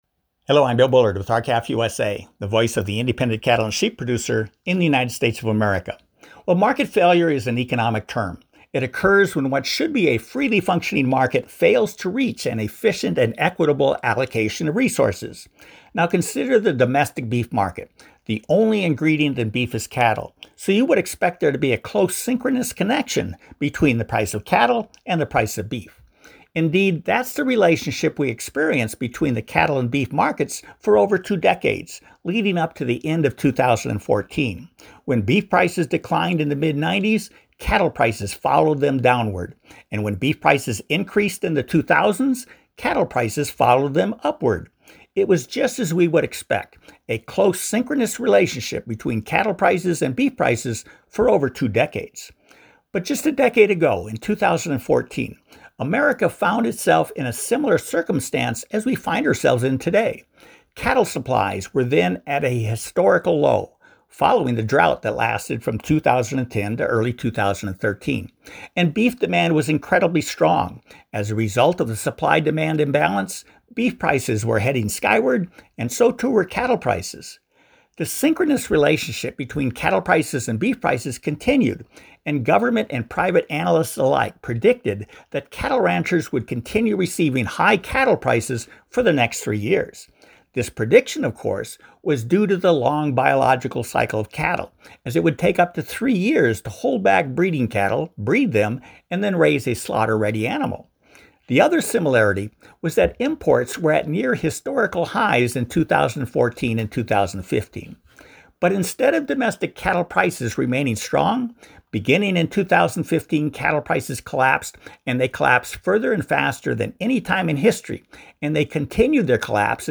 Please find below R-CALF USA’s weekly opinion/commentary that identifies the point at which acute market failure distorted the domestic beef market and explains how it happened.